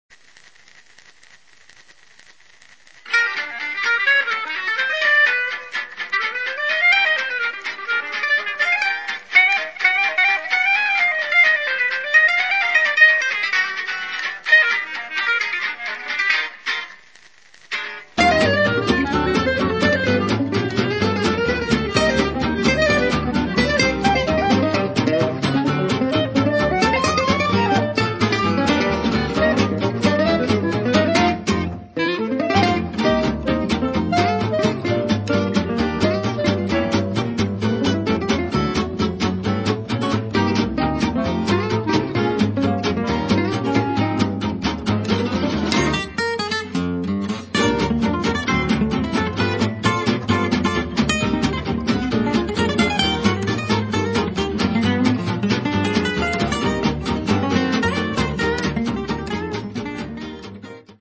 guitar
double bass
cello
clarinet